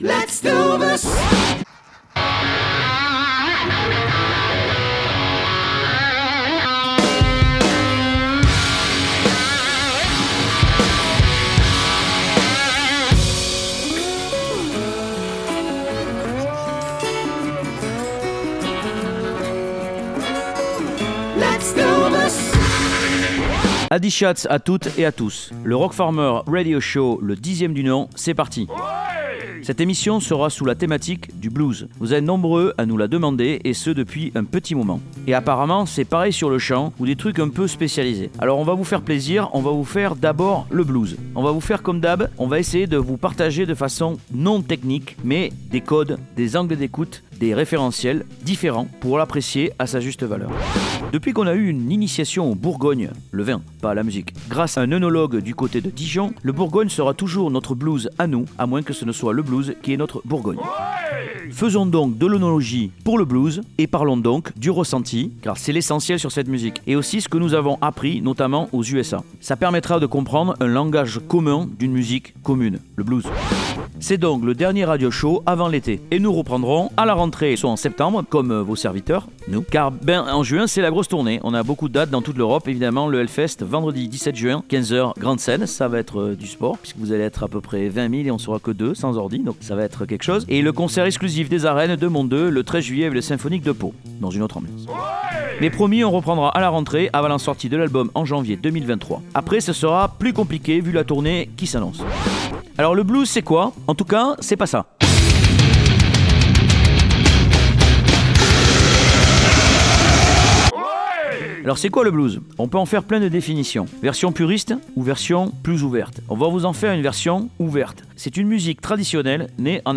L' émission des Musiques ancrées et organiques